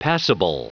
Prononciation du mot passible en anglais (fichier audio)
Prononciation du mot : passible